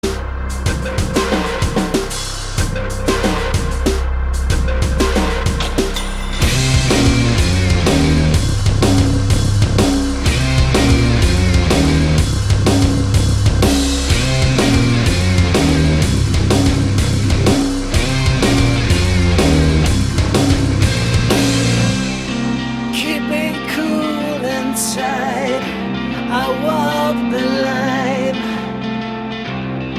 stunning rock singing